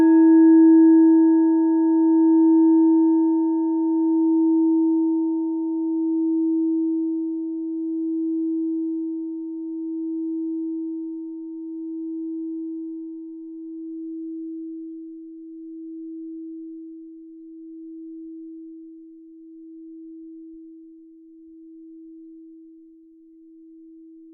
Planetentonschale Nepalesische Klangschale Nr.30 7.Chakra (Scheitelchakra)
Klangschale Nepal Nr.30
(Ermittelt mit dem Filzklöppel)
klangschale-nepal-30.wav